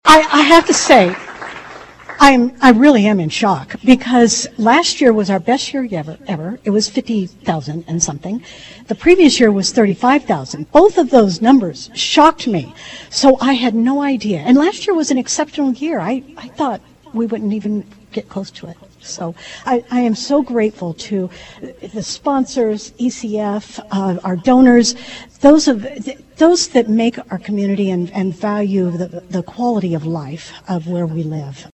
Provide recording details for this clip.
There were signs of a massive Match Day before Tuesday’s official check presentation ceremony — but most people on hand were totally shocked at the individual check amounts and the final total announced at Flinthills Mall as part of live coverage on KVOE.